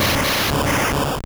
Cri d'Électrode dans Pokémon Or et Argent.